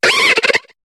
Cri de Feuiloutan dans Pokémon HOME.